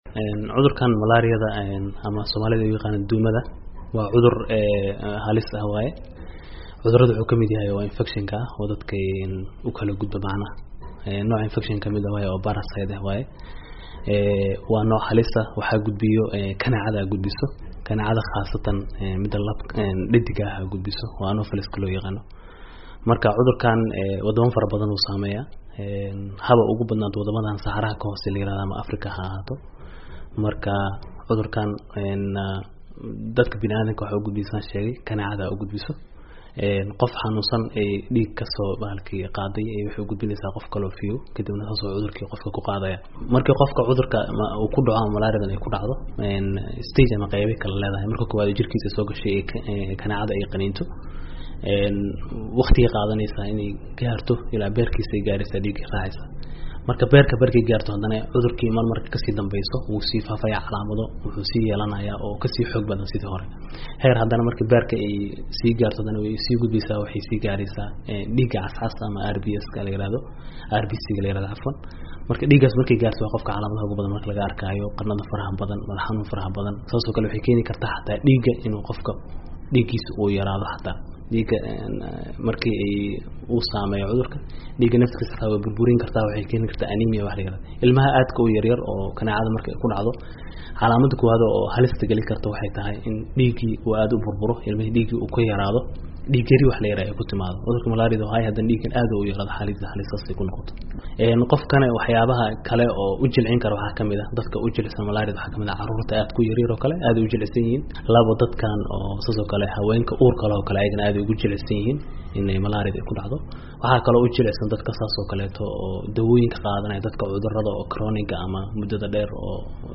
Warbixin maalinta maleeriyada adduunk